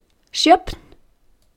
* Listen to pronunciation: Sjöfn,